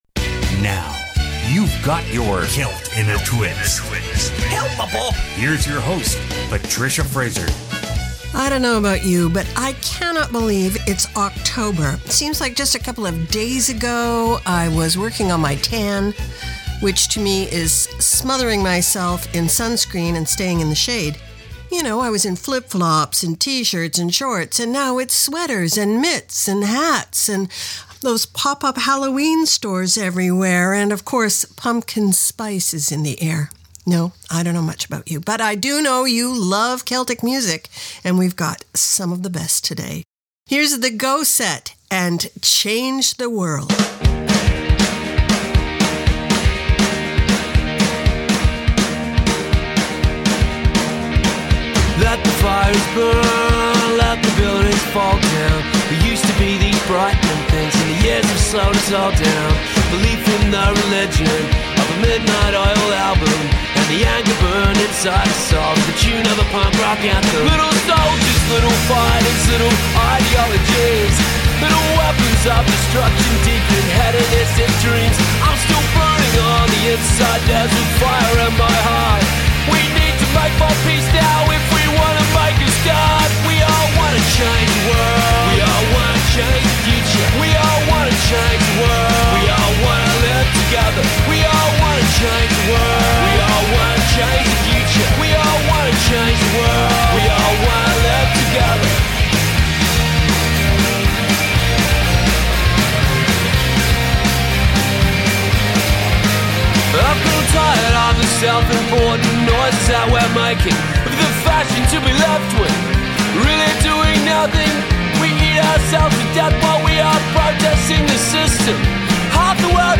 Canada's Contemporary Celtic Radio Hour